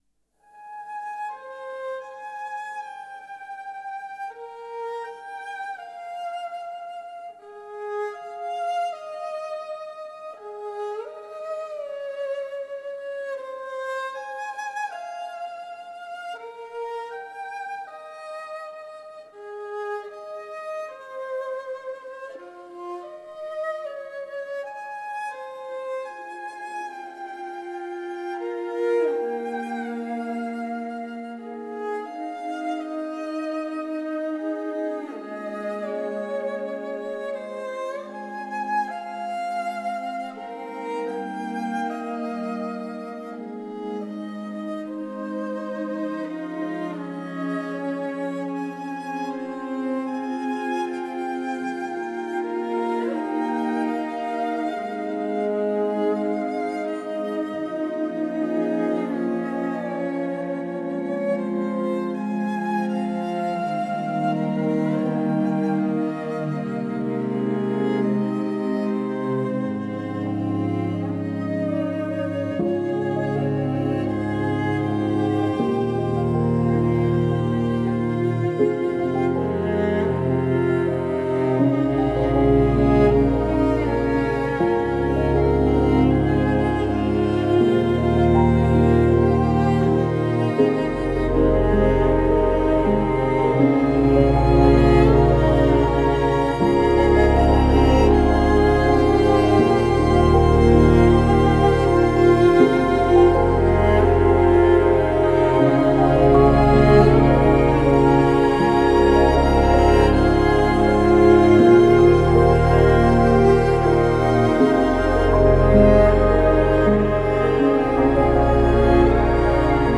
موسیقیدان و آهنگساز ایسلندی